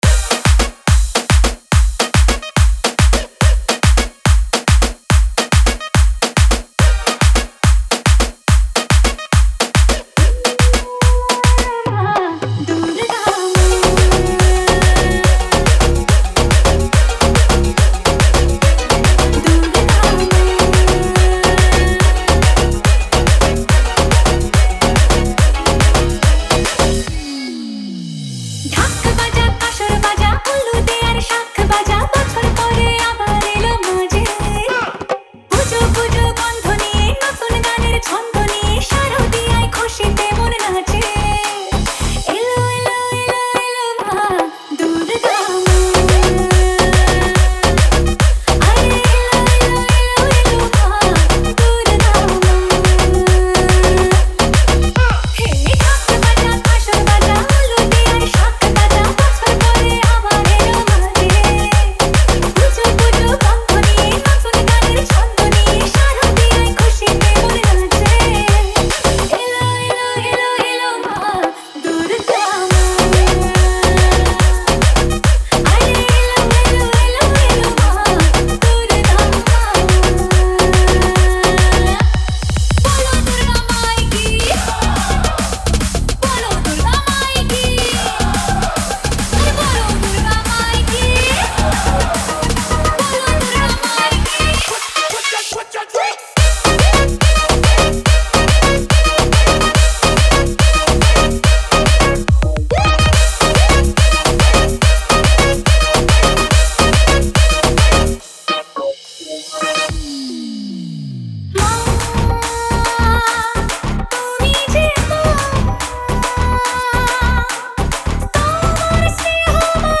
CIRCUIT MIX